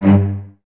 Index of /90_sSampleCDs/Miroslav Vitous - String Ensembles/Cellos/CES Stacc